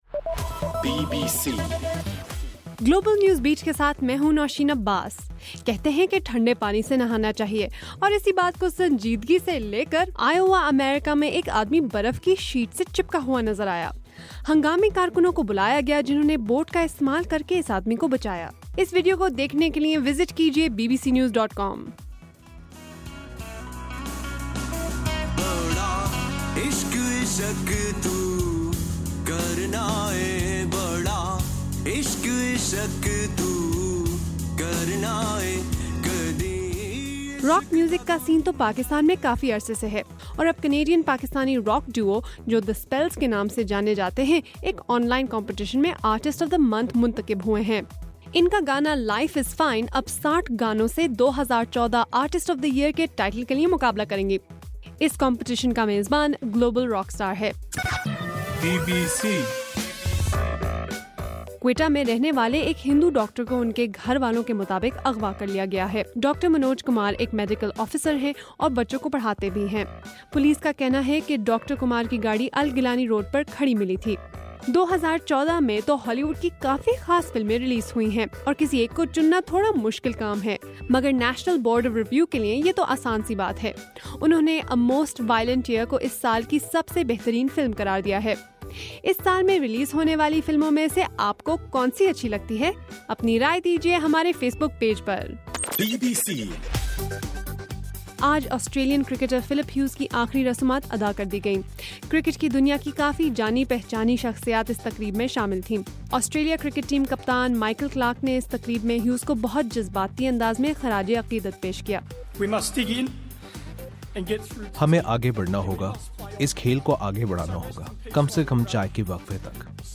دسمبر3 : رات 11 بجے کا گلوبل نیوز بیٹ بُلیٹن